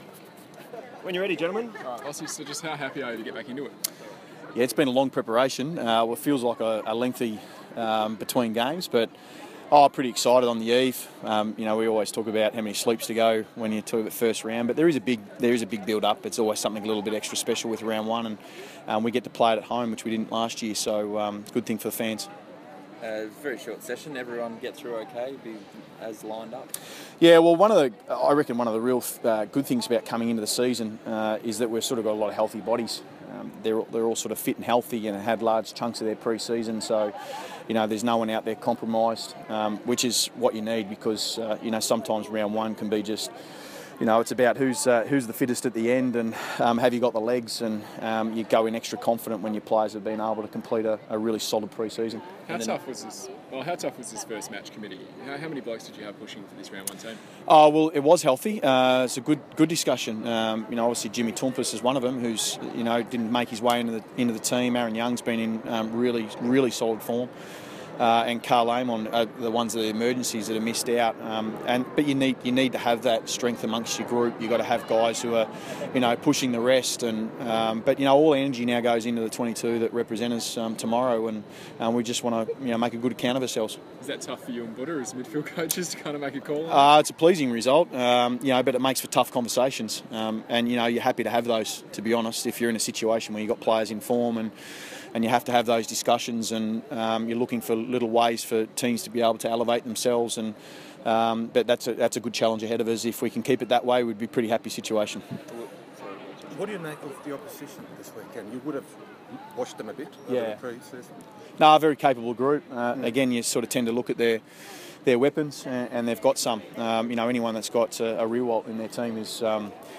Michael Voss press conference - 27 March 2016
Michael Voss speaks to the media ahead of the Power's Round 1 game.